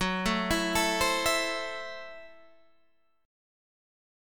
F#m7b5 chord